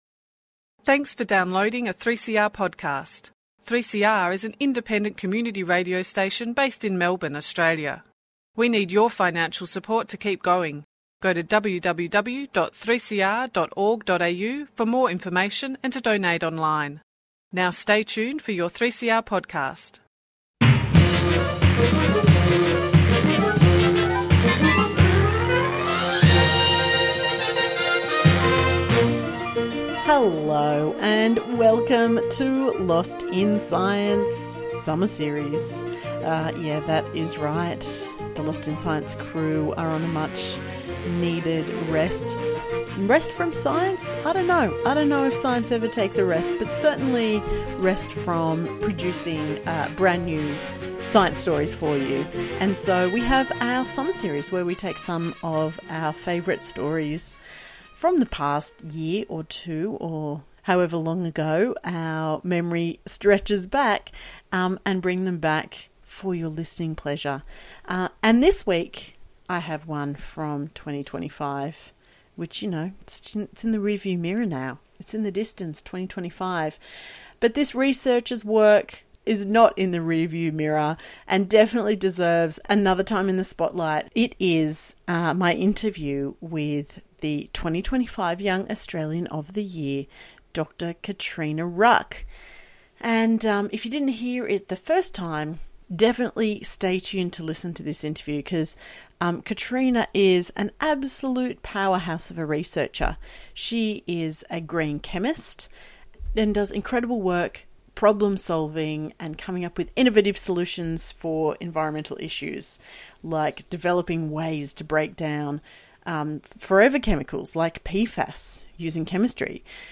as they discuss new research, interview guests and talk frankly about science